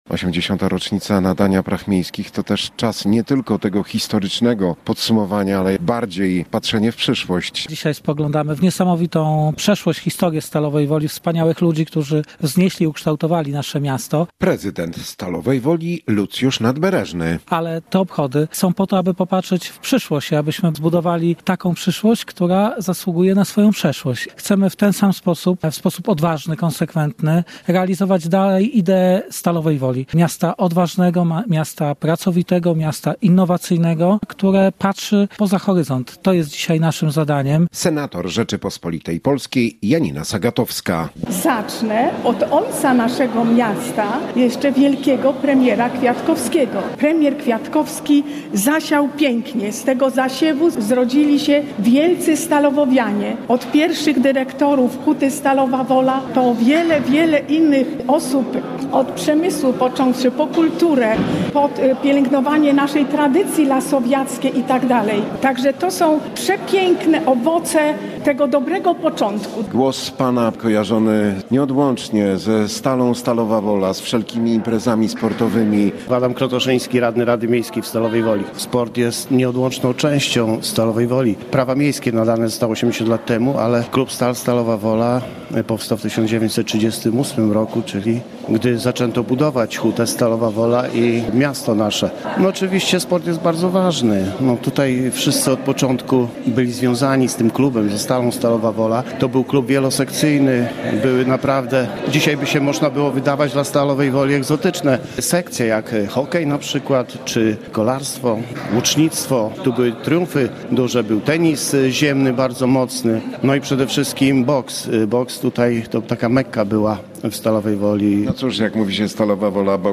W Stalowej Woli w ramach obchodów 80-lecia nadania praw miejskich odbyła się w niedzielę uroczysta sesja rady miasta.
Natomiast prezydent miasta Lucjusz Nadbreżny, odnosząc się do przeszłości, mówił, że należy zadbać o przyszłość następnych pokoleń Stalowej Woli.